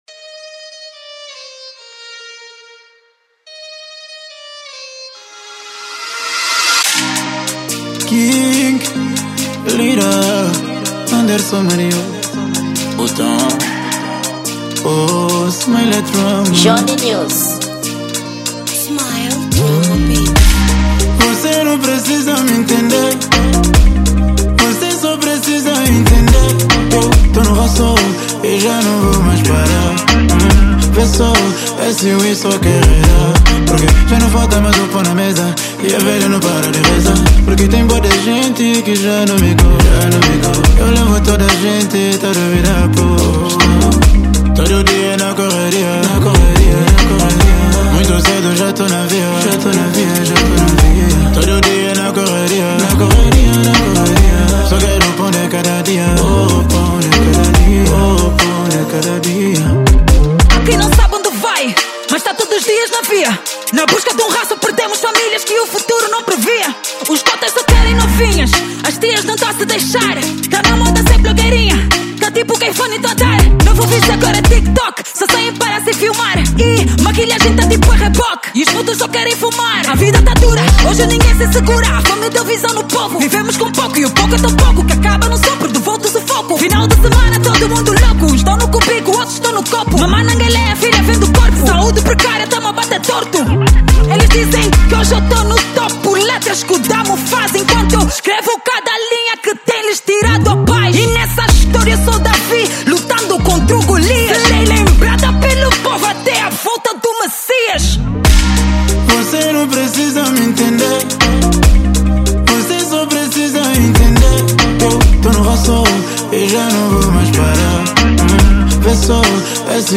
Rap
Gênero: Drill